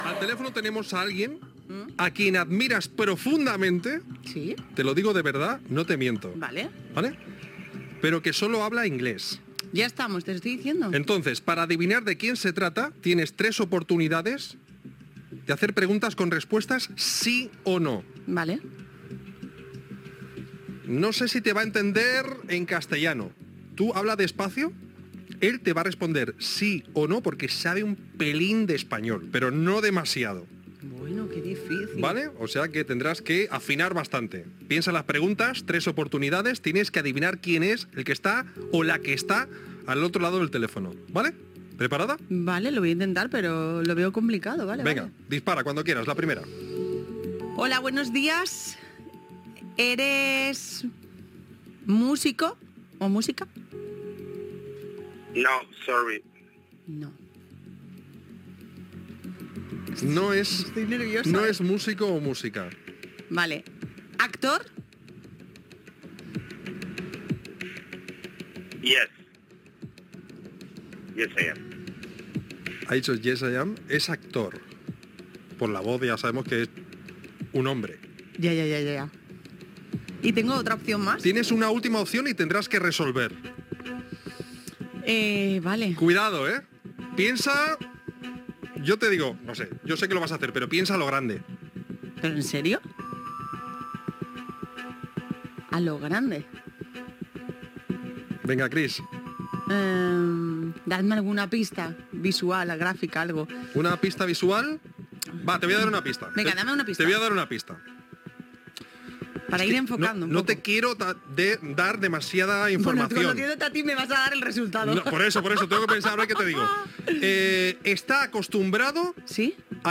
Intent d'encertar quina persona està a l'altra banda del telèfon que resulta ser l'actor Jorge Ponce
Entreteniment
FM